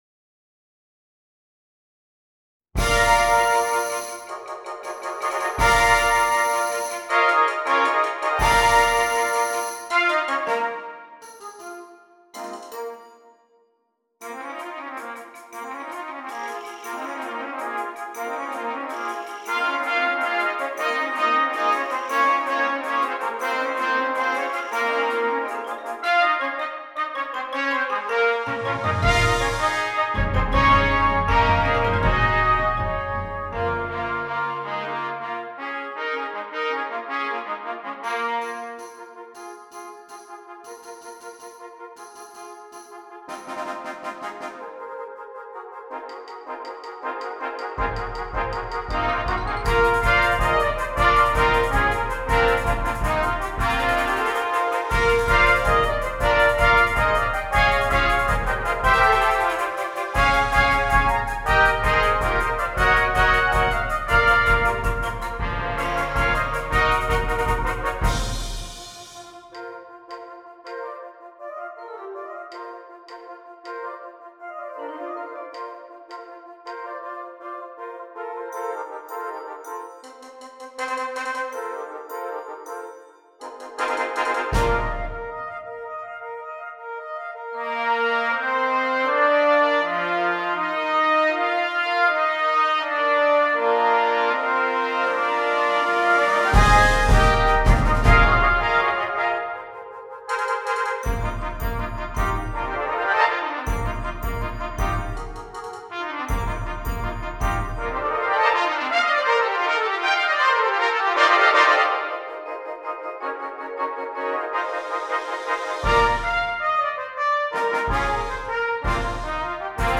6 Trumpets and optional Percussion